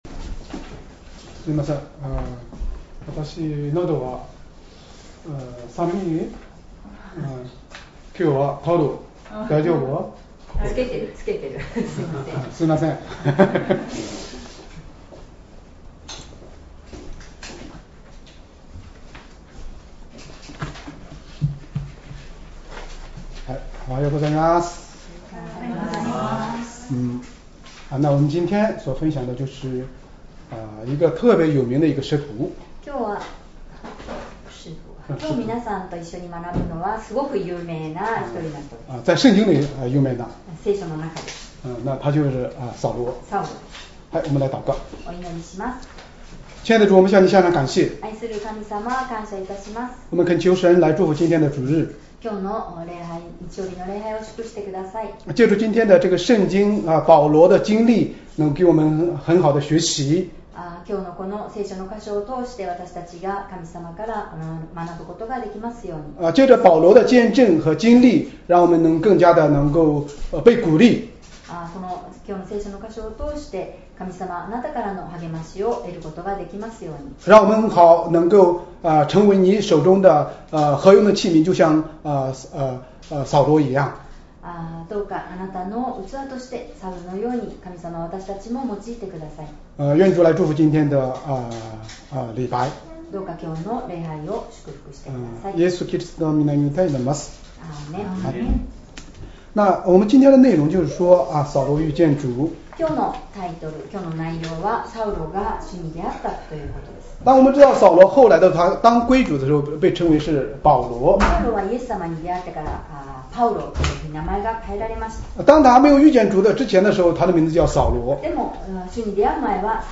Sermon
2025年6月29日 主日礼拝 説教 「主に出会ったサウロ」 聖書 使徒の働き 9章 1-5節 9:1 さて、サウロはなおも主の弟子たちを脅かして殺害しようと息巻き、大祭司のところに行って、 9:2 ダマスコの諸会堂宛ての手紙を求めた。